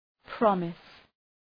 Προφορά
{‘prɒmıs}